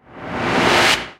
VEC3 Reverse FX
VEC3 FX Reverse 17.wav